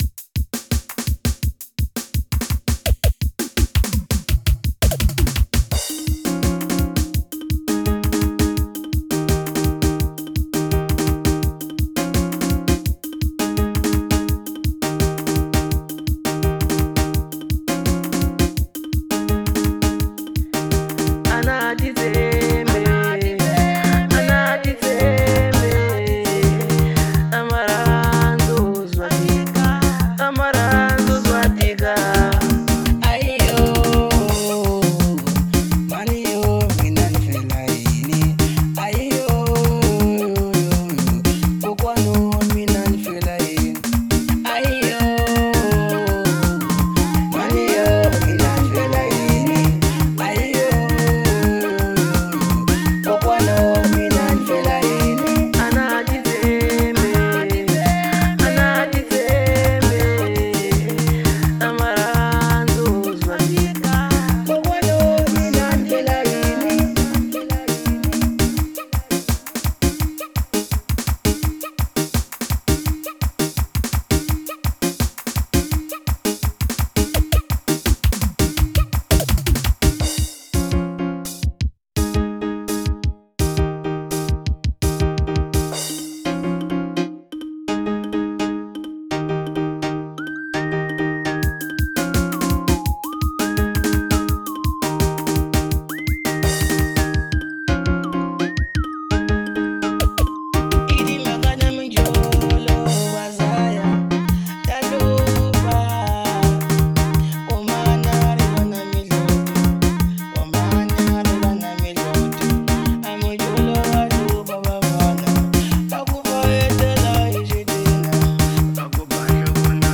03:38 Genre : Xitsonga Size